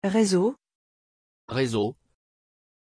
Pronunciation of Rezzo
pronunciation-rezzo-fr.mp3